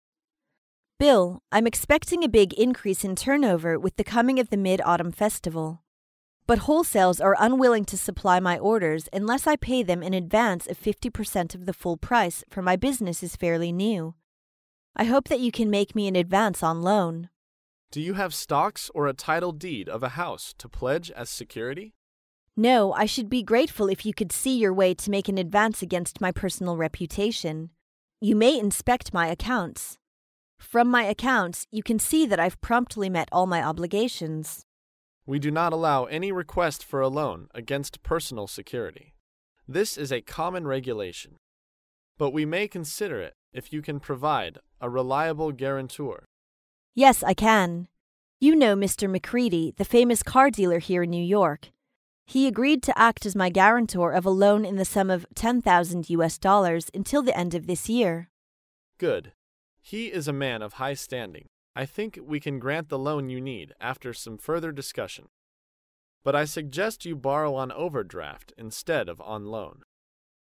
在线英语听力室高频英语口语对话 第430期:申请商务贷款的听力文件下载,《高频英语口语对话》栏目包含了日常生活中经常使用的英语情景对话，是学习英语口语，能够帮助英语爱好者在听英语对话的过程中，积累英语口语习语知识，提高英语听说水平，并通过栏目中的中英文字幕和音频MP3文件，提高英语语感。